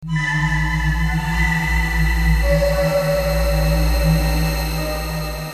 描述：掘墓人的氛围
标签： 120 bpm Ambient Loops Fx Loops 949.57 KB wav Key : Unknown
声道立体声